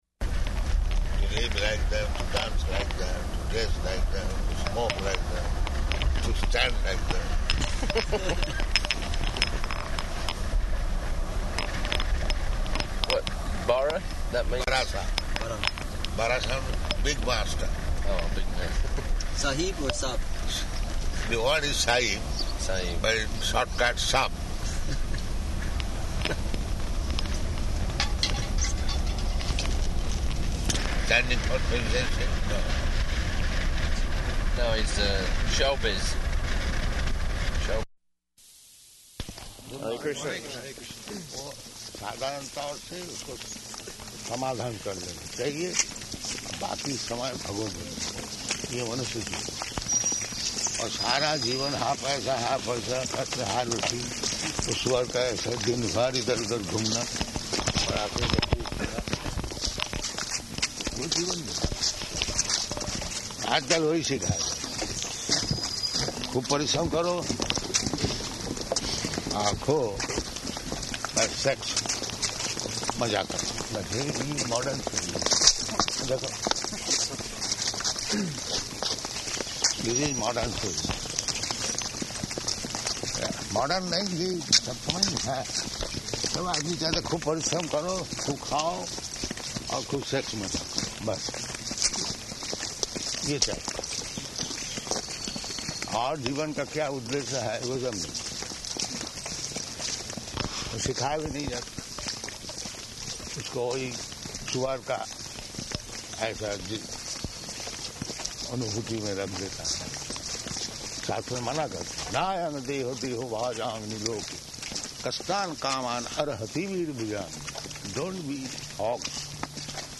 Type: Walk
Location: Toronto